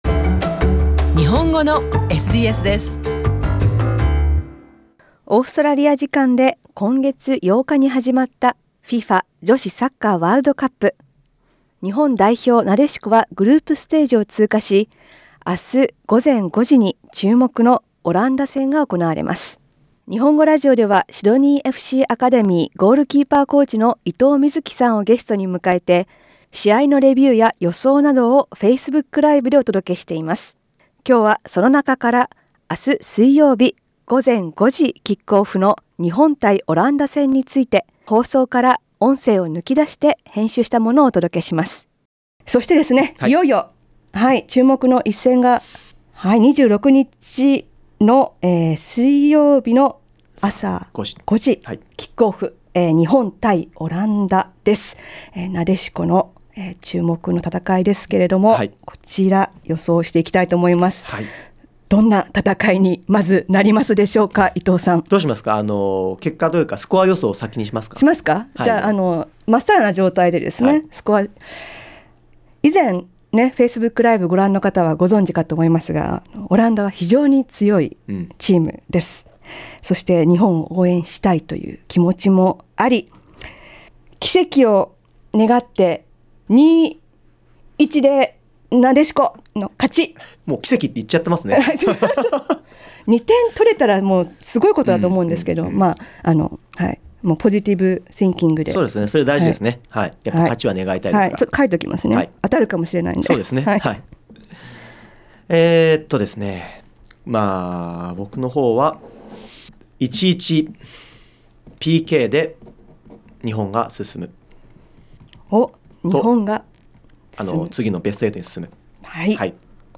このインタビューは、日本語ラジオのフェイスブックで6月24日にライブ配信された内容を編集したものです。